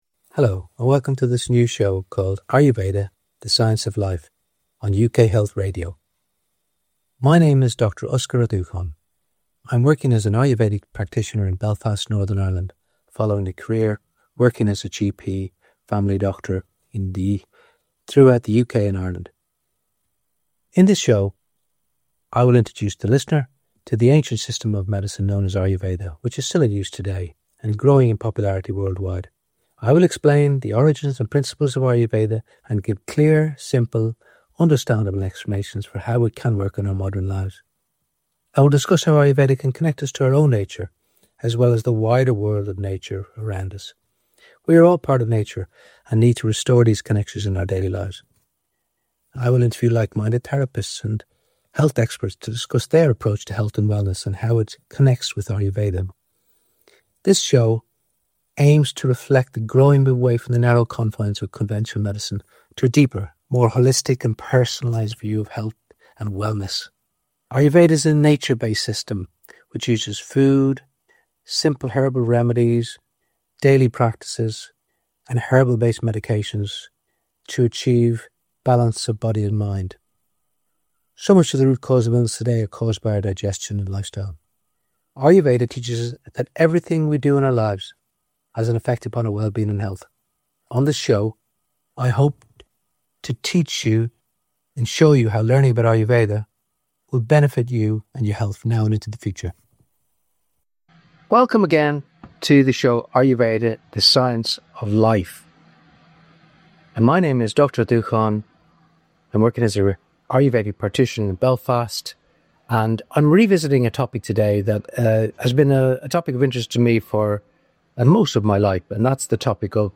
I'll interview therapists and health experts who share a holistic view of wellness.